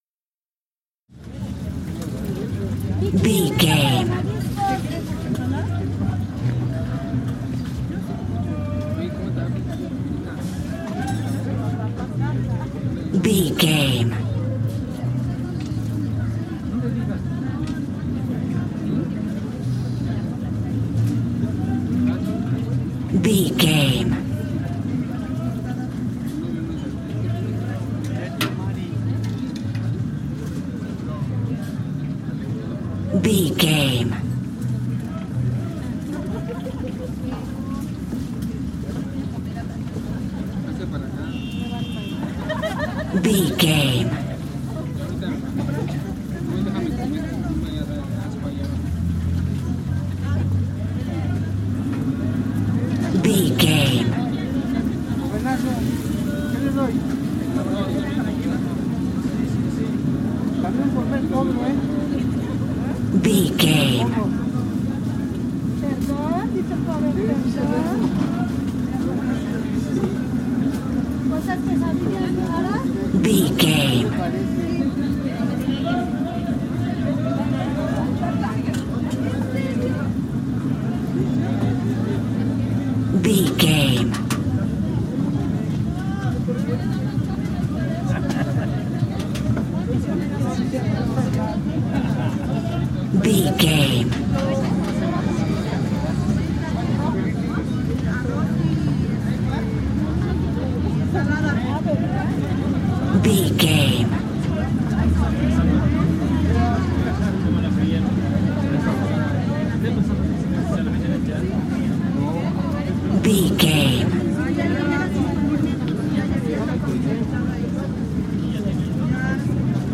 Spanish street market
Sound Effects
urban
chaotic
ambience